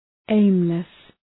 Shkrimi fonetik {‘eımlıs}